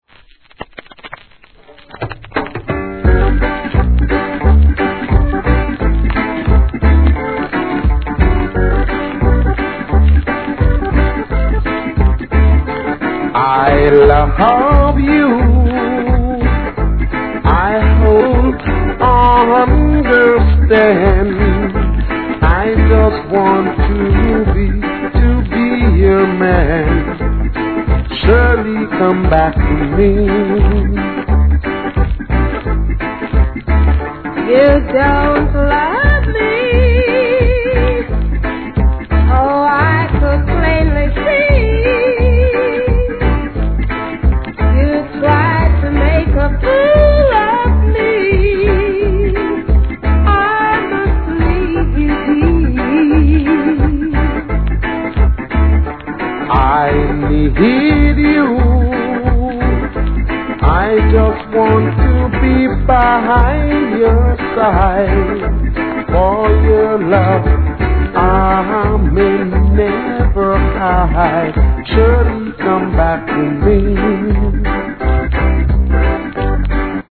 REGGAE
SWEETな好デュエット♪